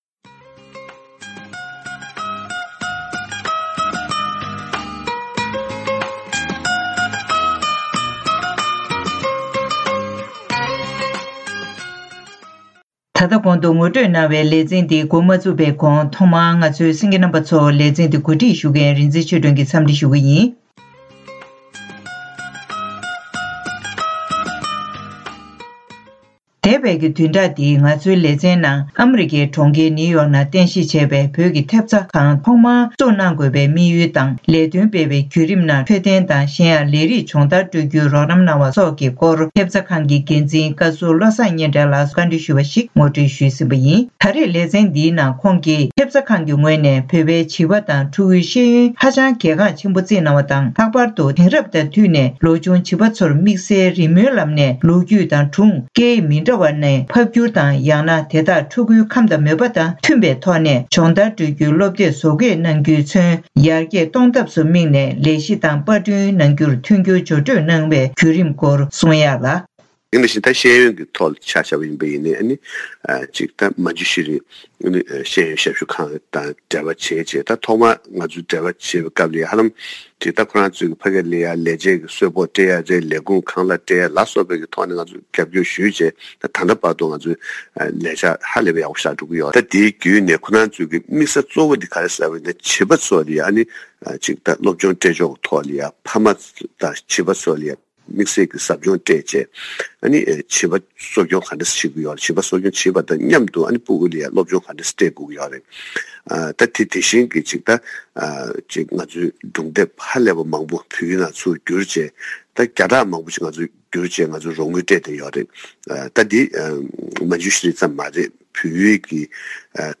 བོད་ཀྱི་ཐེབས་རྩའི་ངོས་ནས་རྒྱ་གར་དང་བལ་ཡུལ་བཅས་སུ་སློབ་ཕྲུག་ཉམས་ཐག་ཚོར་སློབ་ཡོན་རོགས་རམ་གནང་བའི་སྐོར། སྒྲ་ལྡན་གསར་འགྱུར།